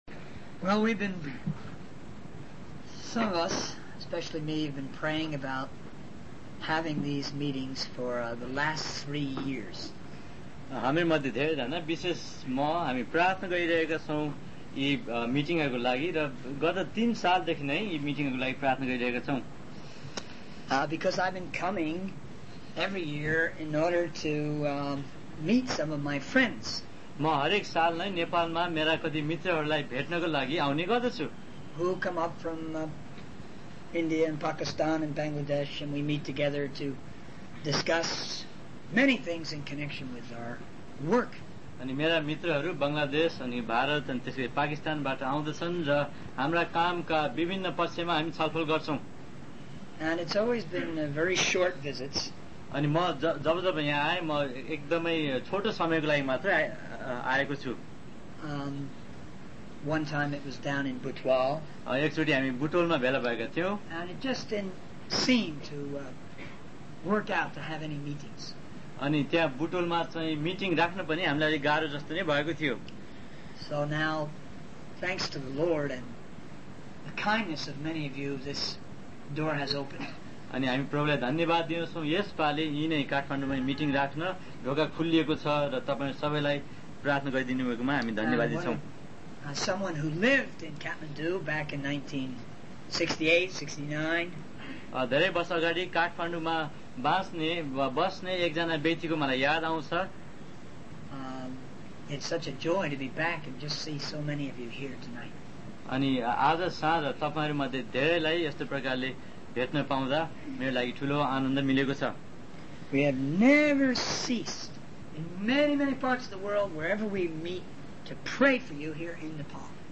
In this sermon, the speaker shares his personal journey of faith and the challenges he faced in his search for truth.